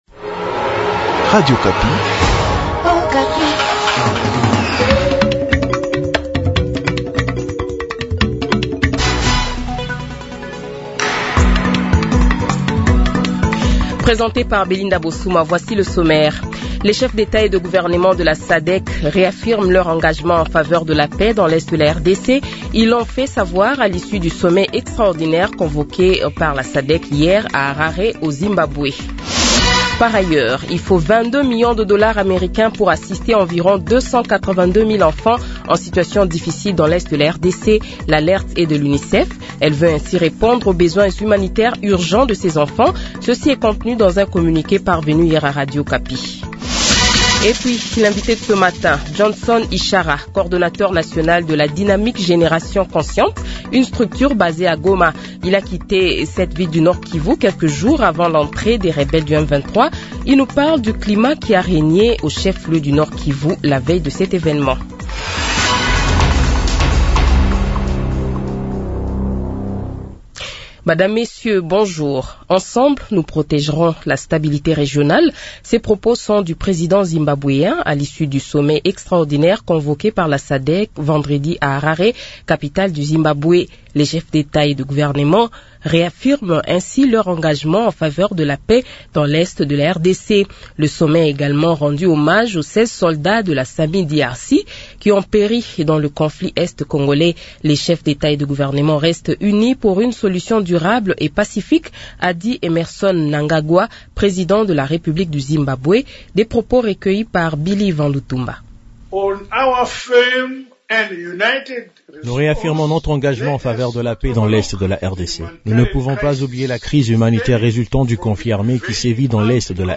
Le Journal de 7h, 1er Fevrier 2025 :